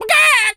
chicken_cluck_scream_long_01.wav